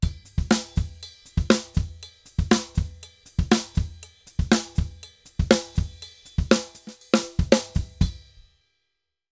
Drums_Salsa 120_3.wav